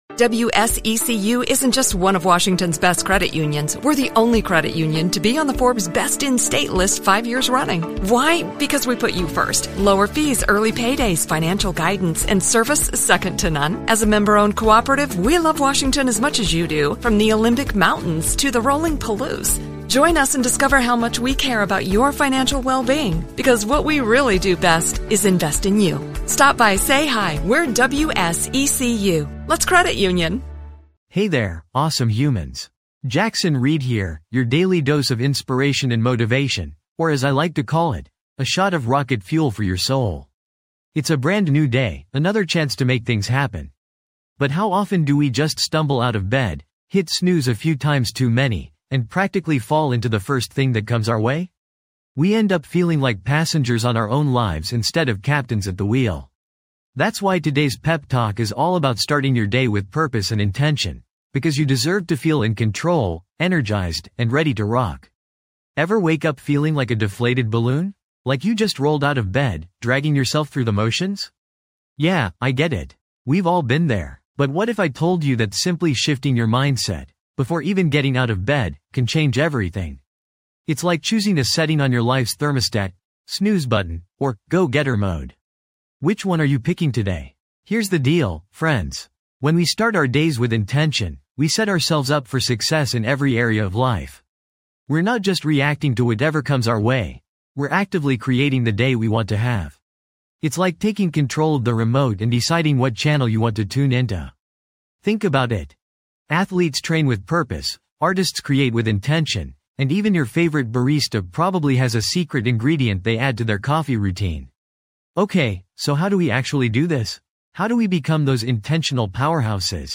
Podcast Category: Personal Development, Motivational Talks, Success Stories
This podcast is created with the help of advanced AI to deliver thoughtful affirmations and positive messages just for you.